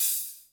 HIHAT HO 8.wav